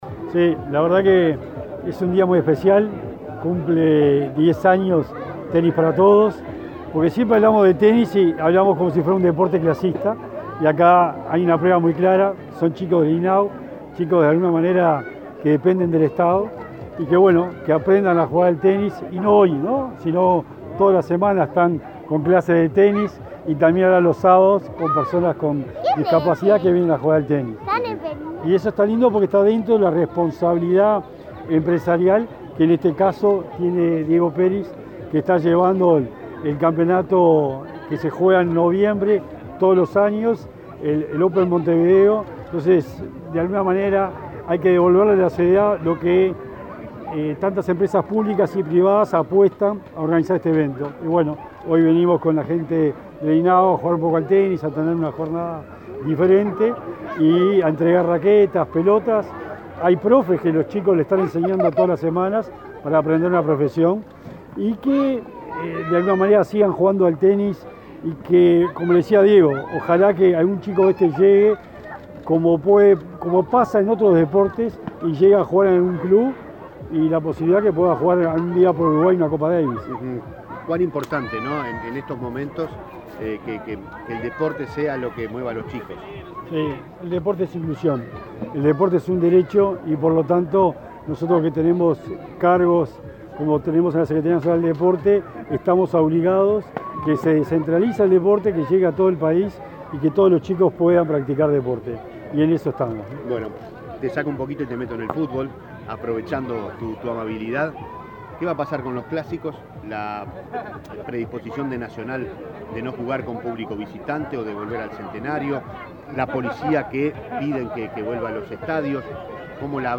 Declaraciones del secretario del Deporte, Sebastián Bauzá
Antes, Bauzá dialogó con la prensa.